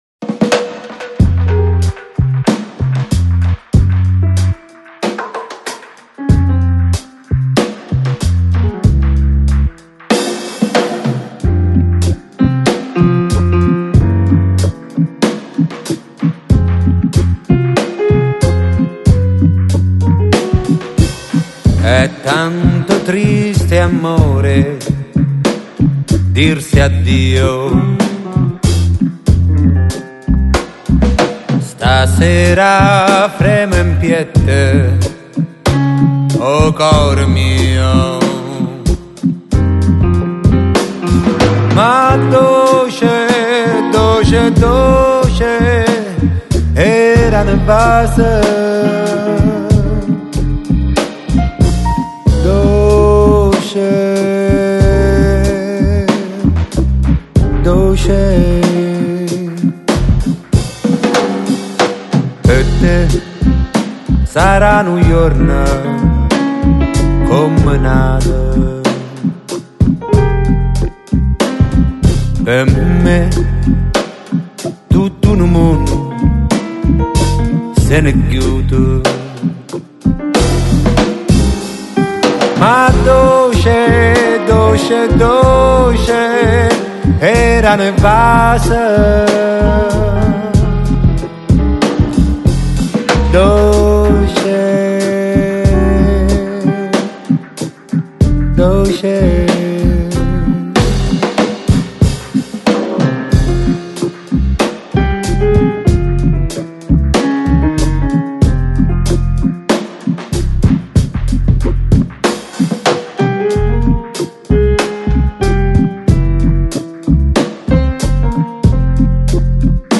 Lo-Fi, Lounge, Chillout, Deep House, Nu Jazz